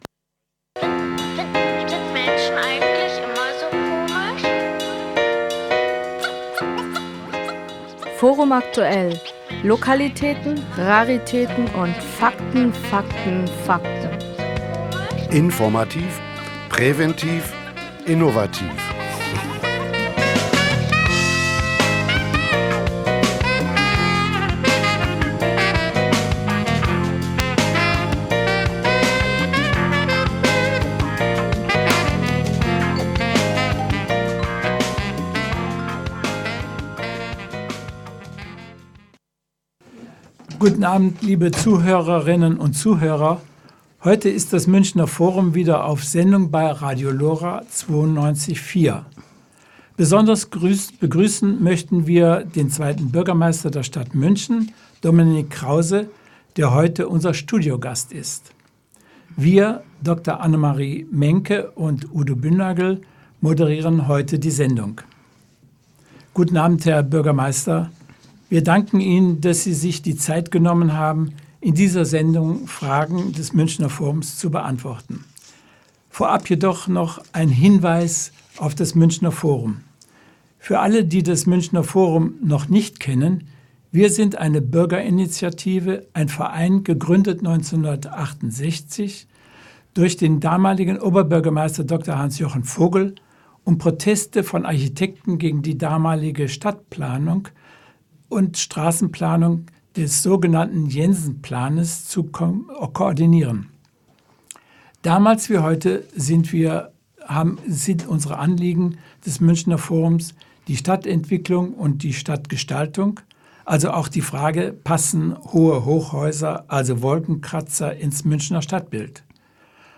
Kulturpolitik in Münchenmit Bürgermeister Krause ~ Forum Aktuell (die Radio-Sendung des Münchner Forums) Podcast